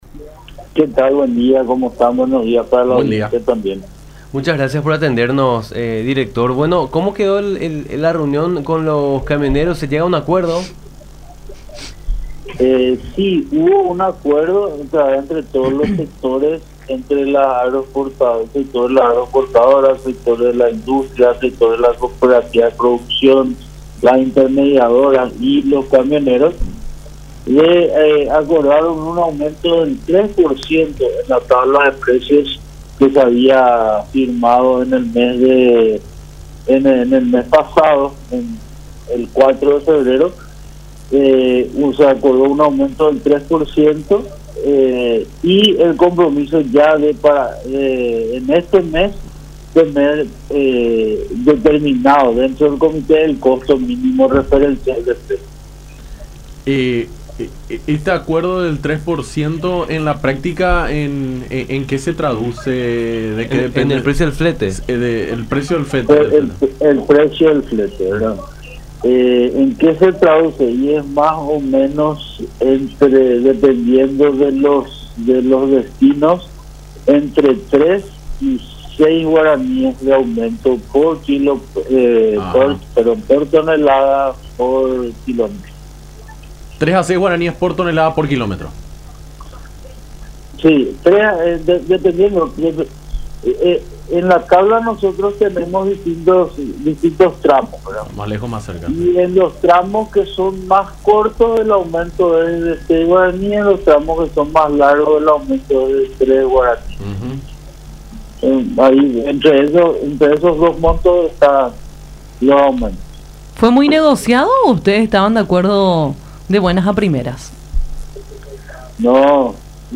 Nosotros siempre actuamos como mediadores, porque tuvimos una reunión bastante complicada, pero finalmente se pudo llegar a ese acuerdo”, dijo Juan José Vidal, titular de la DINATRAN, en diálogo con Nuestra Mañana por La Unión.